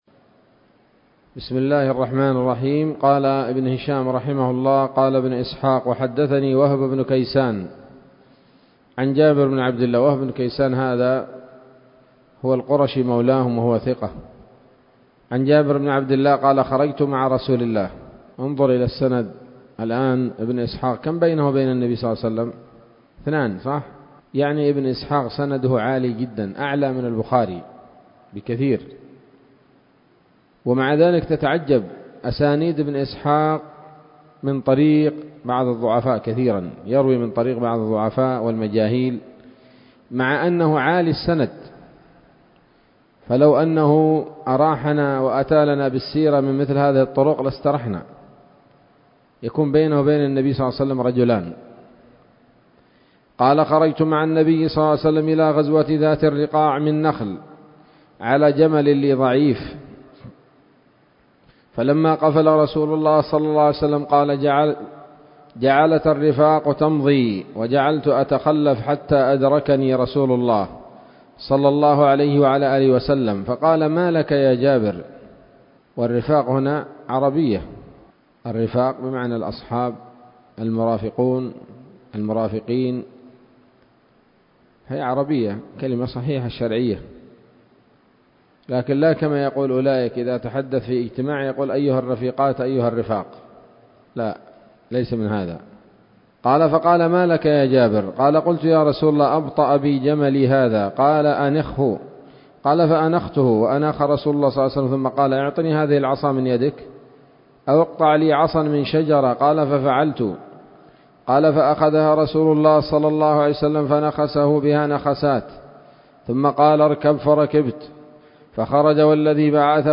الدرس الرابع والتسعون بعد المائة من التعليق على كتاب السيرة النبوية لابن هشام